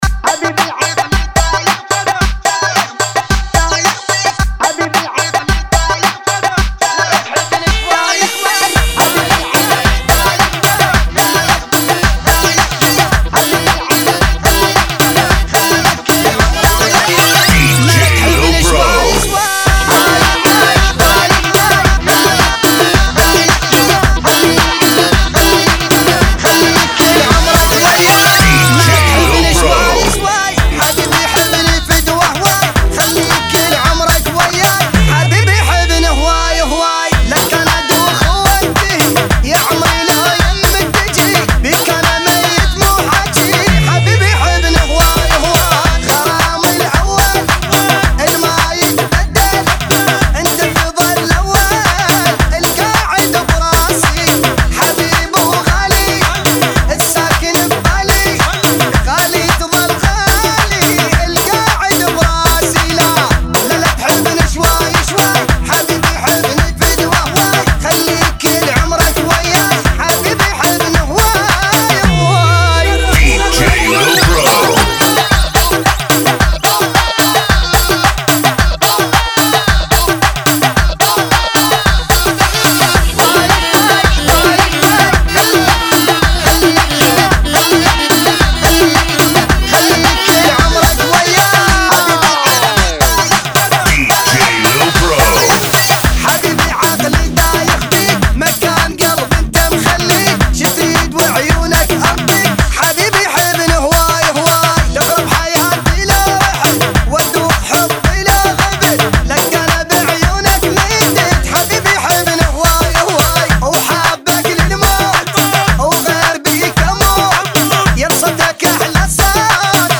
[ 110 Bpm }